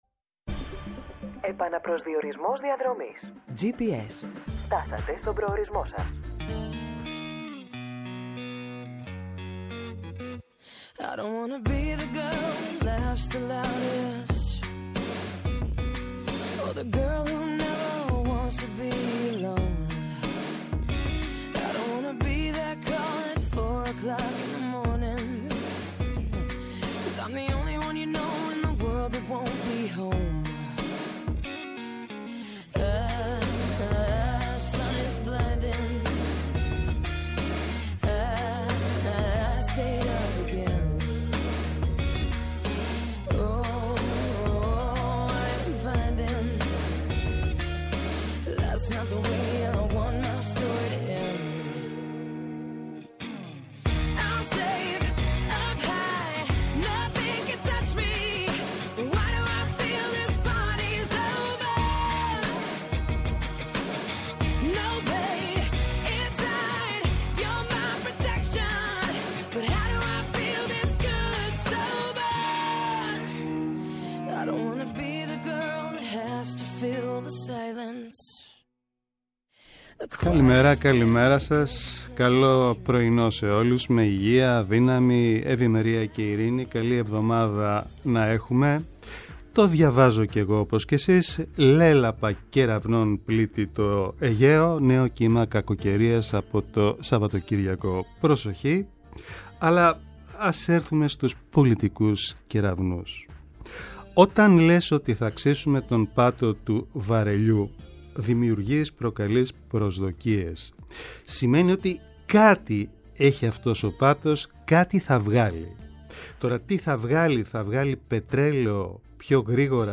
αποκαλυπτικές συνεντεύξεις και πλούσιο ρεπορτάζ